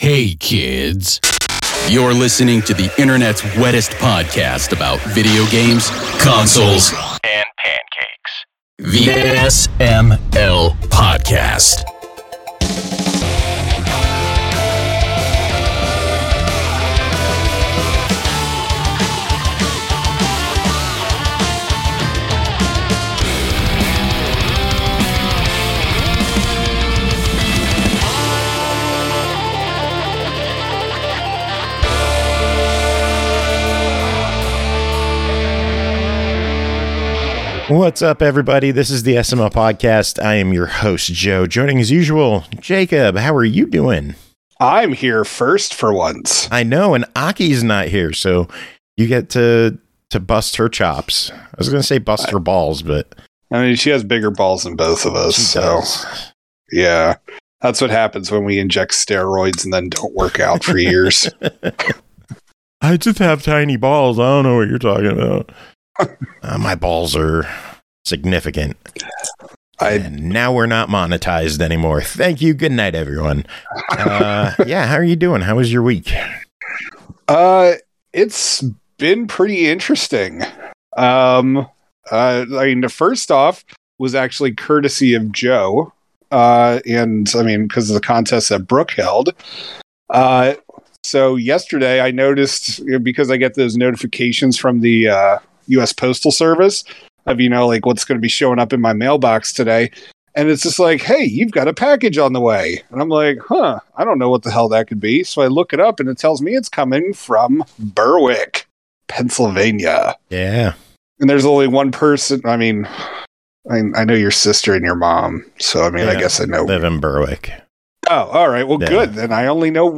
It’s a standard news episode with reviews and absolutely nothing spectacular happens.